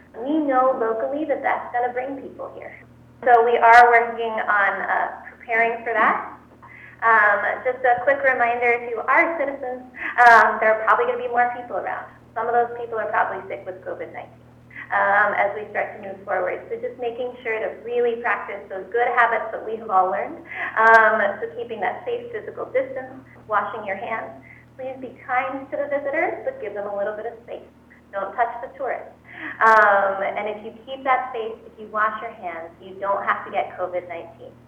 PORT ANGELES – With announcements this week that national, state and county parks will be opening for day use on May 5, County Health Officer Dr. Unthank said at Friday morning’s briefing that we can expect a lot of folks from outside the community to come visit.